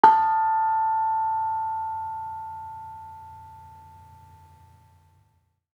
Bonang-A4-f.wav